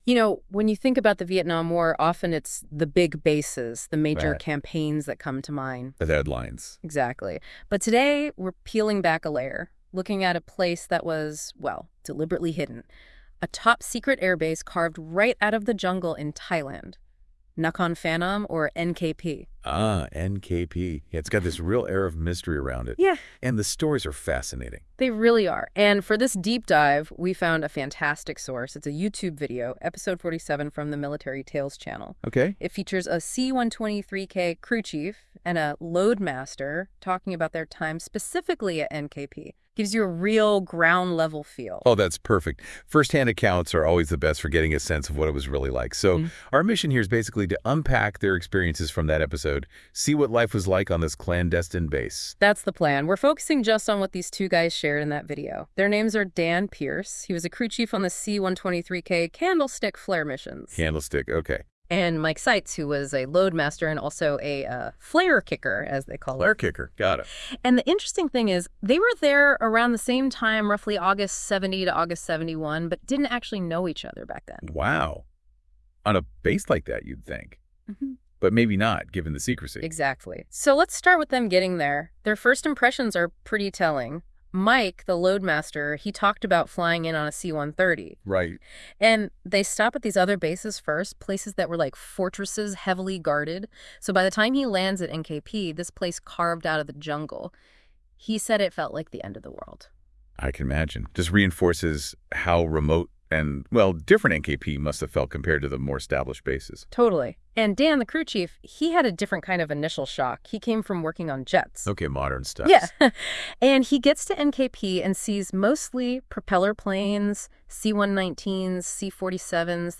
Military Tales Episode 47 brings us face-to-face with two veterans of the Vietnam War who served at the remote and at-the-time highly classified Nakhon Phanom Royal Thai Air Force Base (NKP).